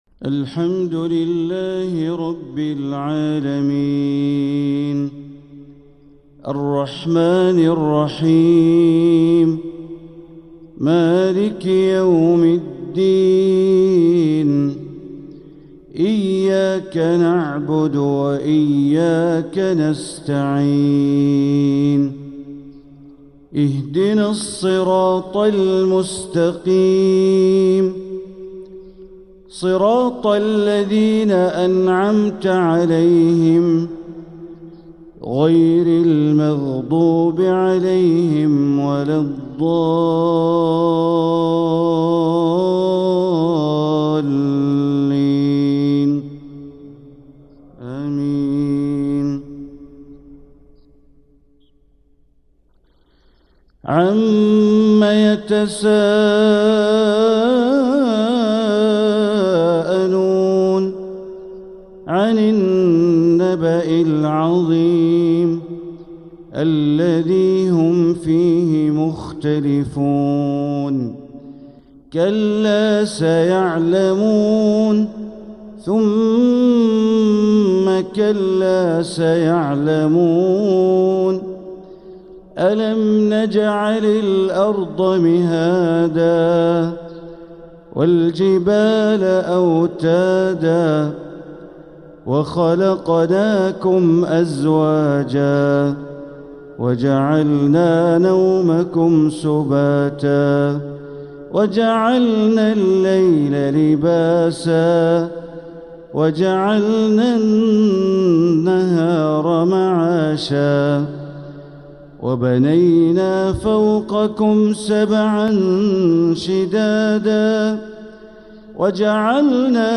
سلسلة اللآلئ الأصيلة لتلاوات الشيخ بندر بليلة لتلاوات شهر رجب 1446 | الحلقة الثالثة والسبعون > سلسلة اللآلئ الأصيلة من تلاوات الشيخ بندر بليلة > الإصدارات الشهرية لتلاوات الحرم المكي 🕋 ( مميز ) > المزيد - تلاوات الحرمين